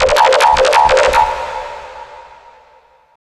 OLDRAVE 1 -R.wav